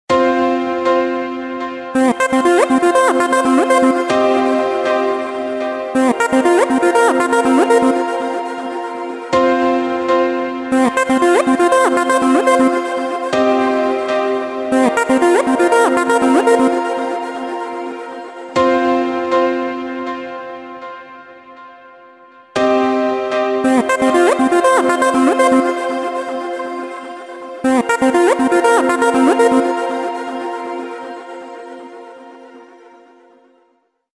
• Качество: 128, Stereo
электронная музыка
без слов
клавишные
космические
космический призывный рингтон